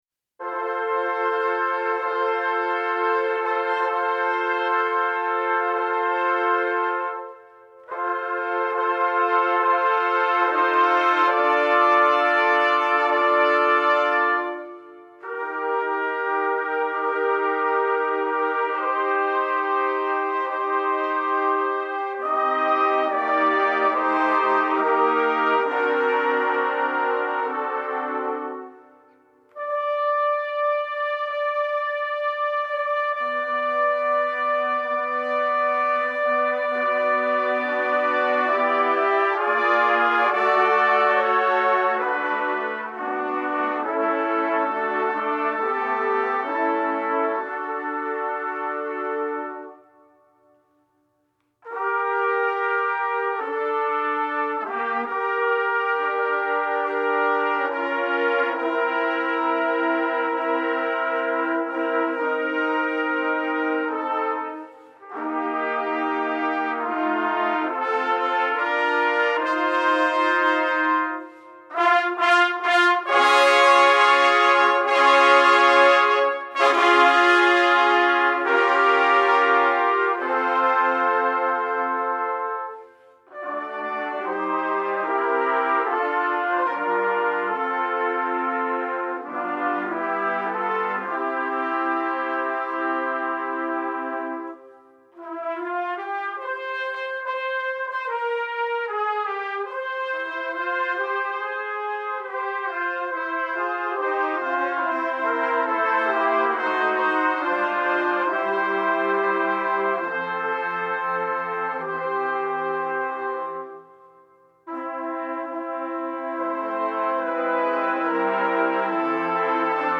Trumpet Ensemble